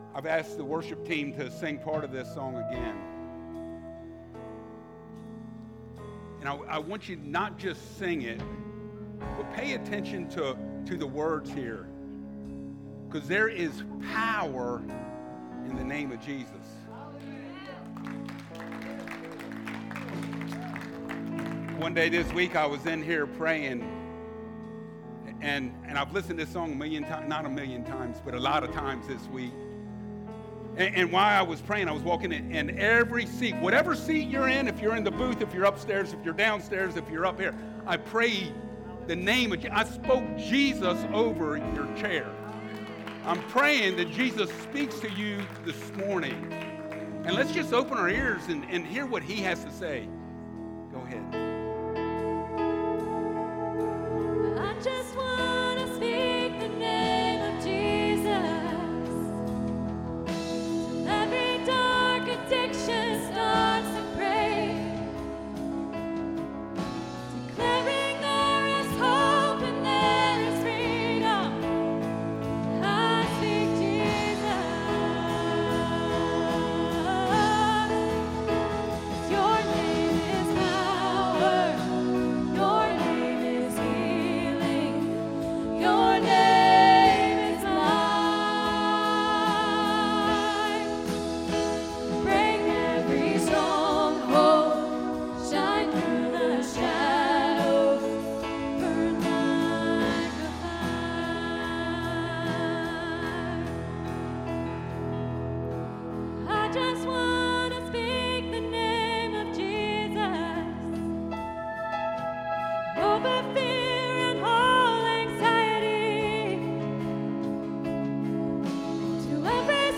Philippians 4:4-7 Service Type: Sunday Mornings How do you get peace indescribable?